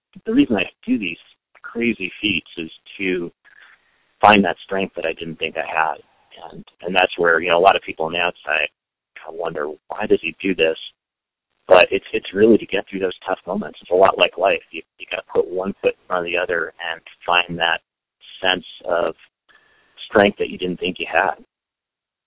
JUREK EXPLAINS WHY HE DOES “THESE CRAZY FEATS.”